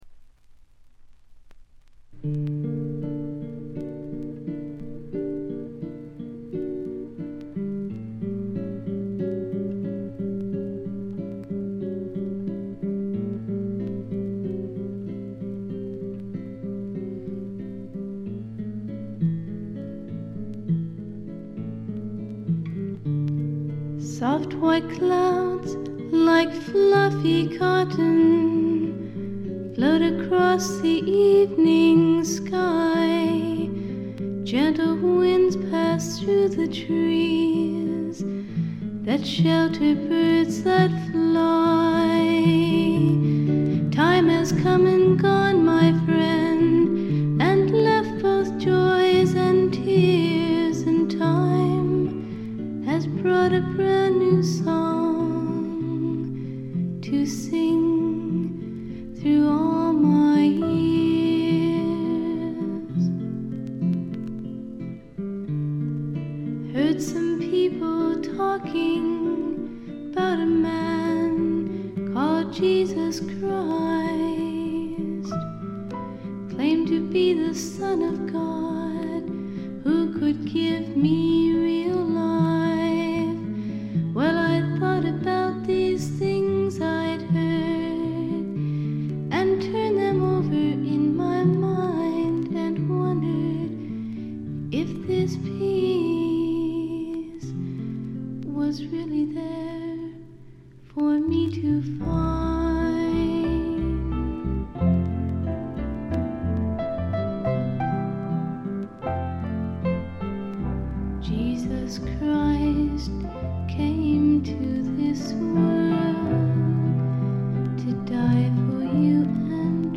ところどころで軽微なチリプチ。気になるようなノイズはありません。
演奏はほとんどがギターの弾き語りです。
試聴曲は現品からの取り込み音源です。